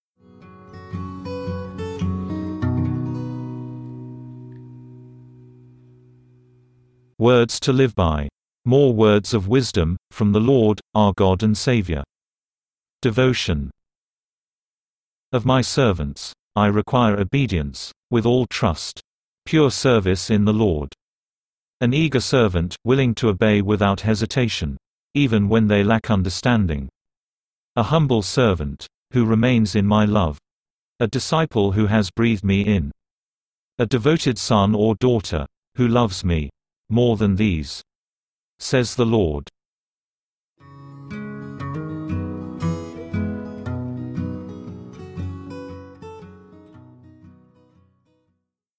File:WTLB 117 Devotion (read by text-to-speech).mp3 - The Volumes of Truth
WTLB_117_Devotion_(read_by_text-to-speech).mp3